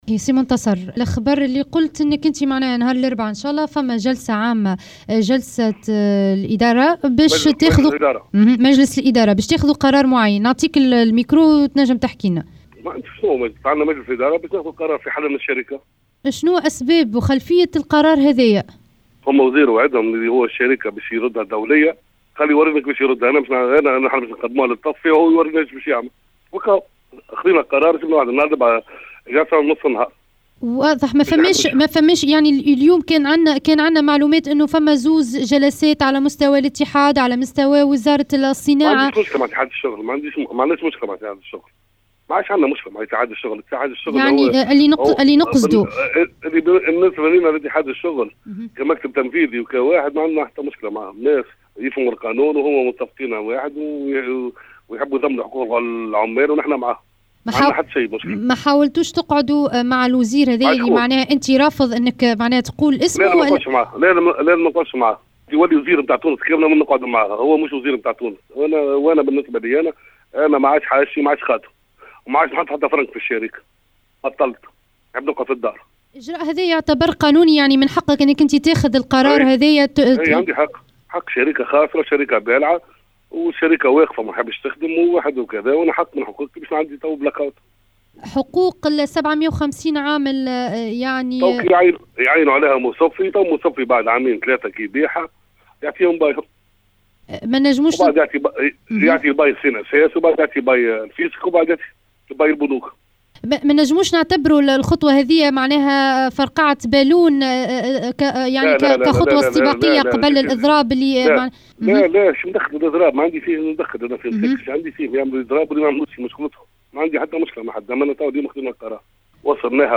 في تصريح لاذاعتنا